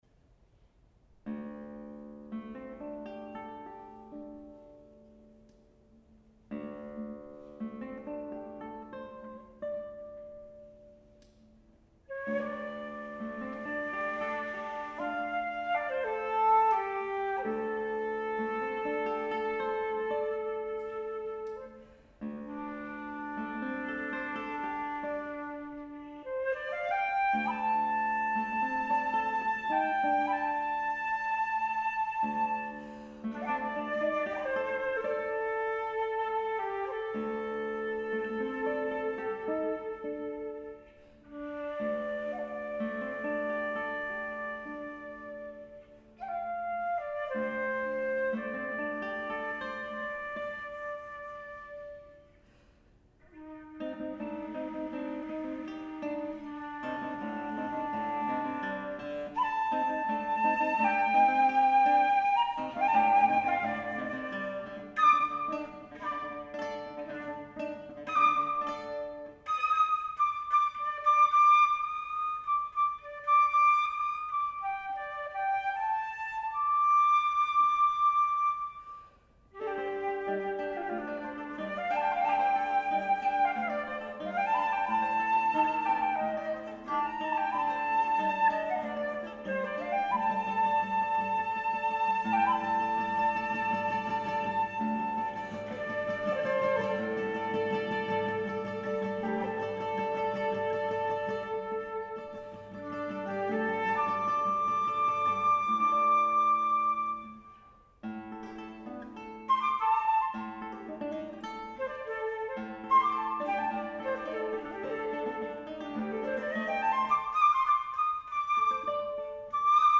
flutist